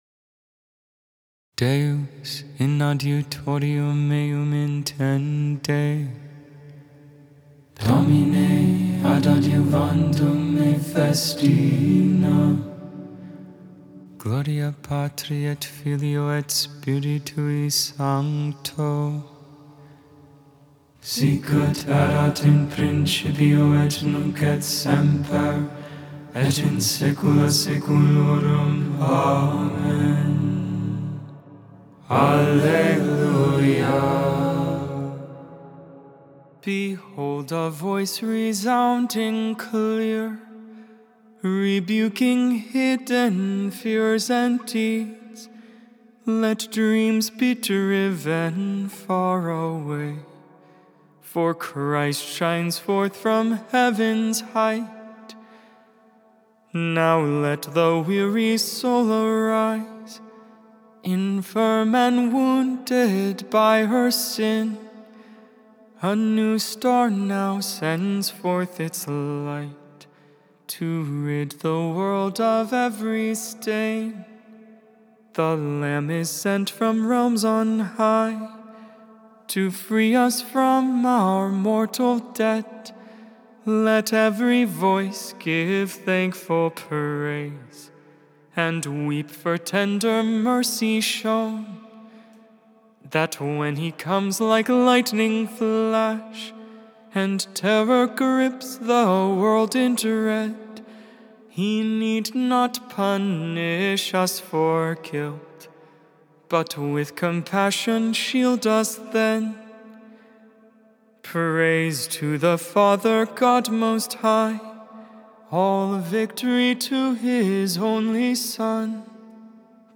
12.10.24 Lauds, Tuesday Morning Prayer